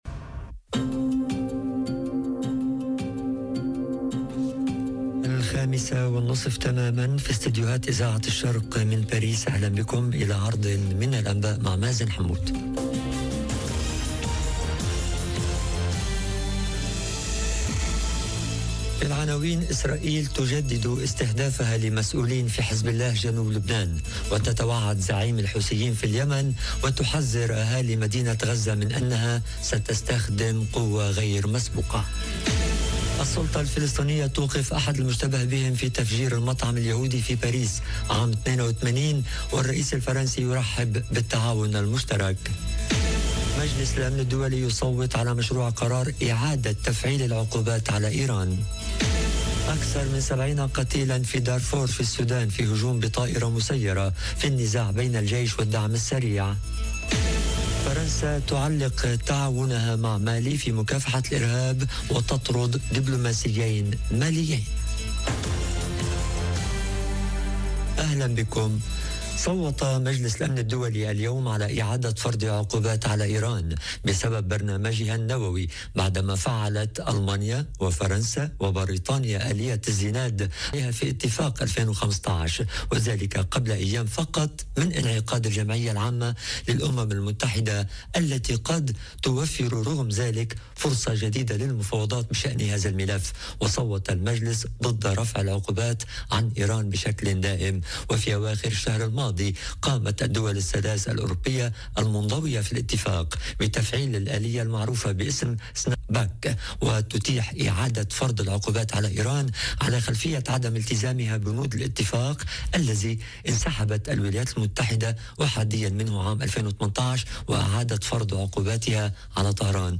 نشرة أخبار المساء: إسرائيل تجدد استهدافها لمسؤولين في حزب الله جنوب لبنان، وتتوعد زعيم الحوثيين في اليمن، وتحذر اهالي مدينة غزة من انها ستستخدم قوة غير مسبوقة - Radio ORIENT، إذاعة الشرق من باريس